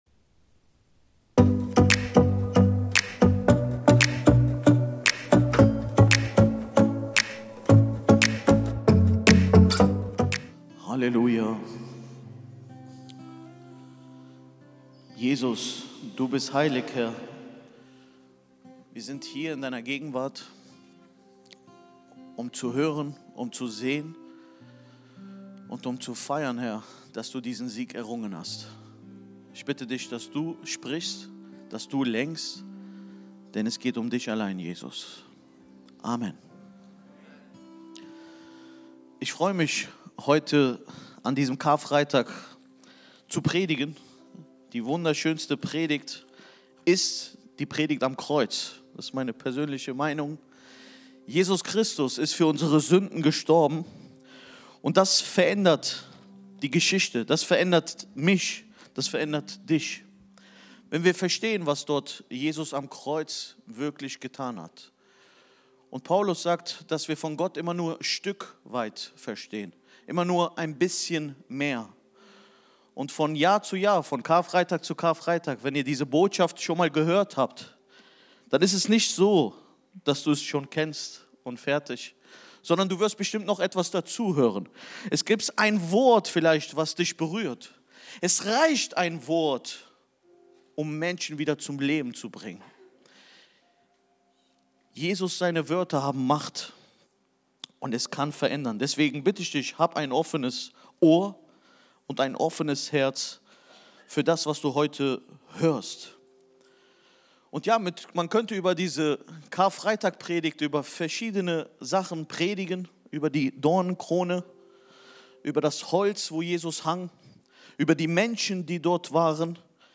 Video und MP3 Predigten
Kategorie: Sonntaggottesdienst